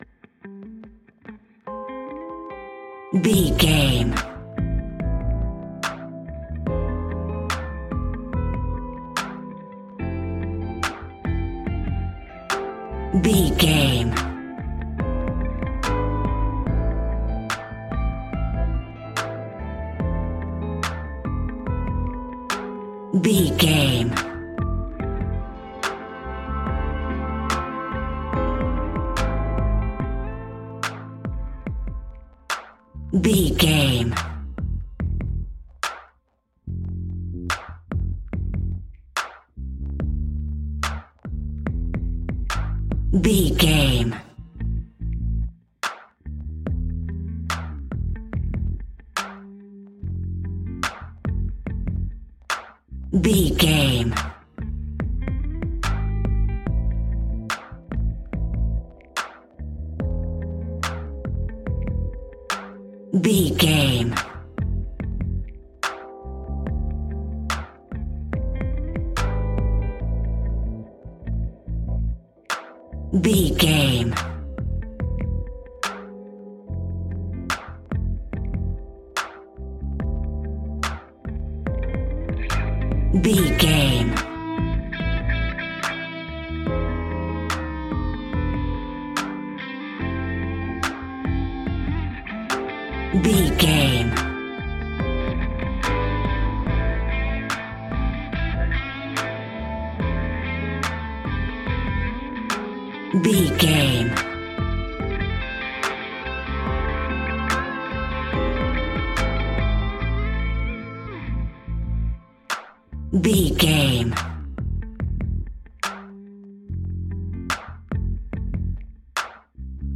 Epic / Action
Fast paced
In-crescendo
Uplifting
Ionian/Major
A♭
hip hop